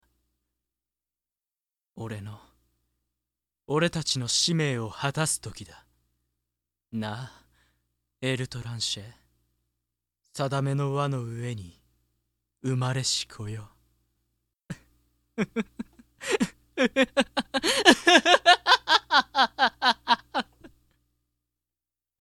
声質は中〜低
銀のゼフェル；ライフォス　悪役